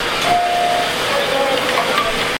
dootdootdoorsclosesubway
ding subway sound effect free sound royalty free Sound Effects